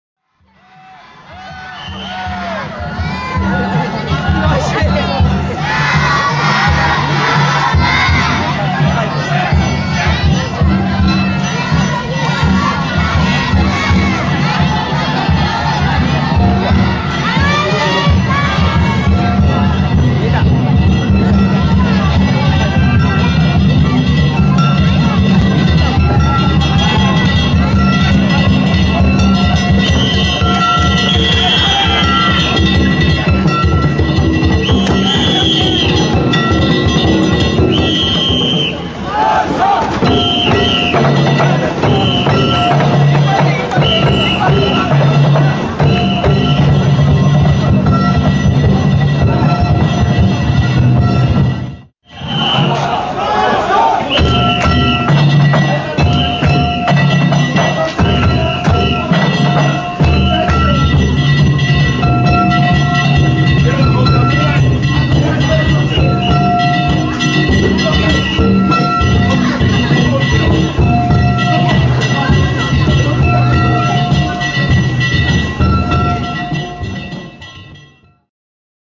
平成２９年７月１９日、福島区の野田恵美須地車夏祭り曳行を見に行ってきました。
交差点手前からお囃子が速くなり、